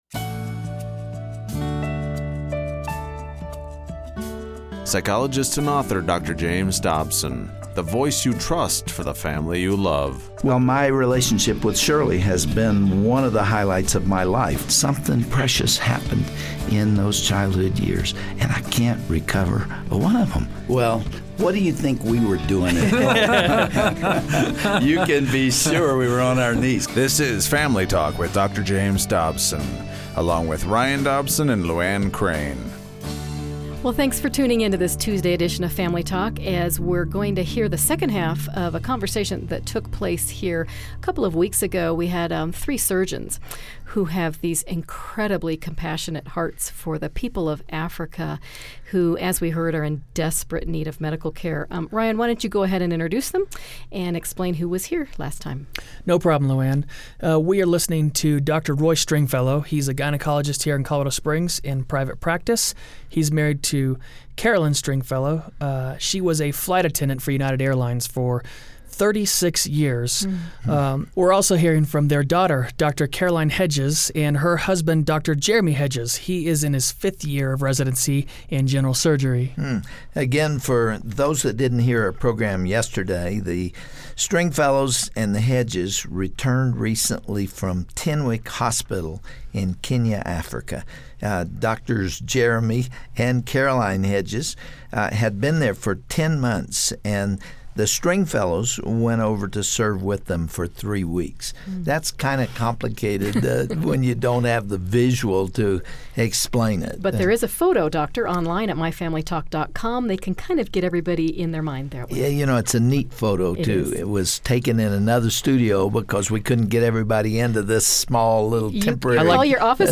Listen as Dr. Dobson's studio guests describe their outreach in Kenya and how their love for the mission field was kindled on a family trip when they were teens.
Host Dr. James Dobson